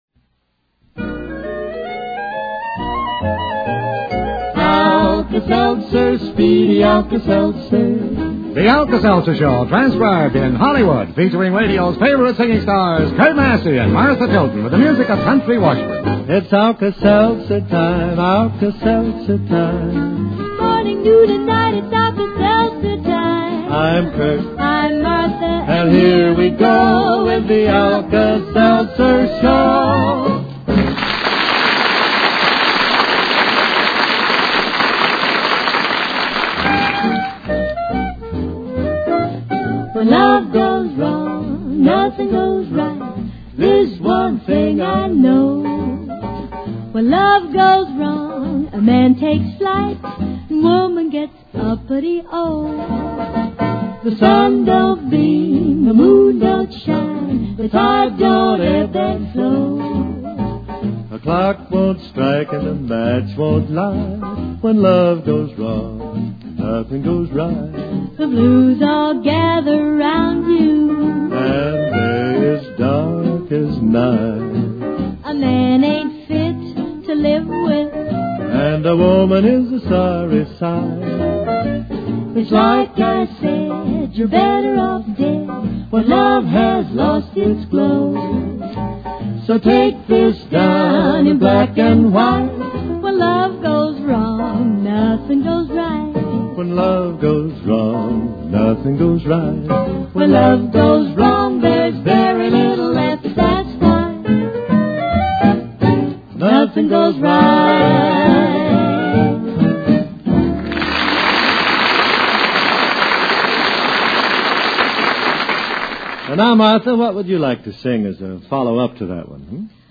Sponsored by: Alka Seltzer, One-A-Day, Miles Nervine. The first tune is "When Love Goes Wrong."